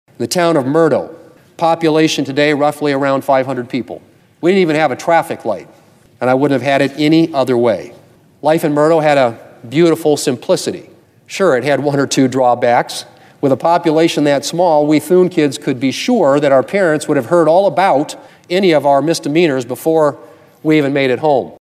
Republican Senator John Thune says he understands rural America because he grew up in a small South Dakota town.